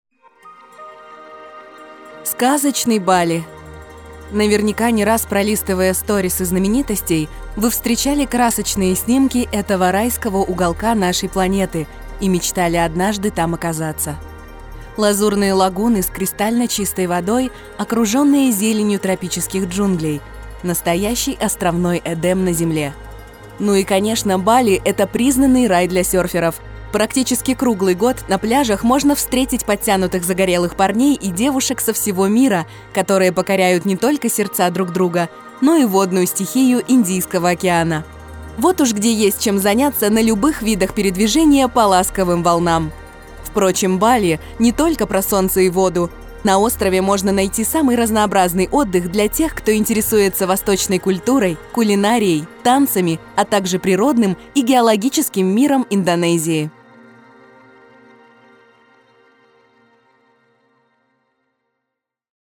Диктор